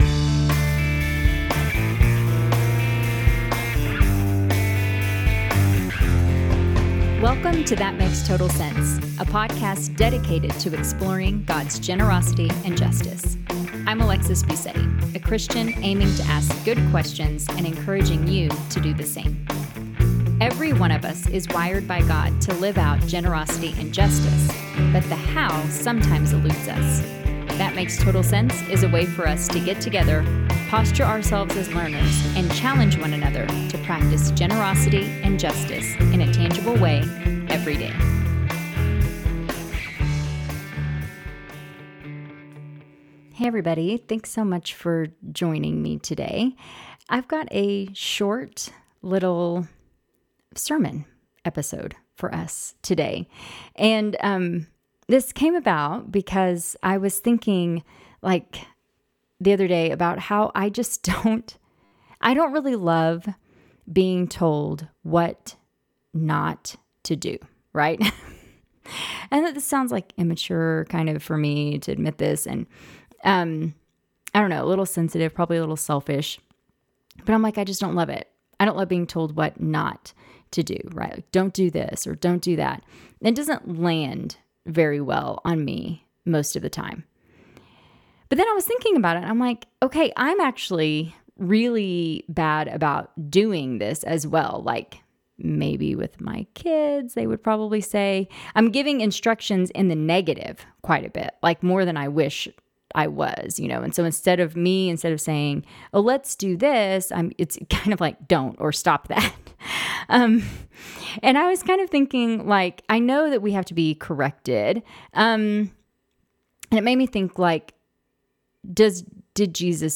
Just a short little sermon on the center part of the Sermon on the Mount where Jesus gives us quite a few "do not" statements on giving, praying, and fasting.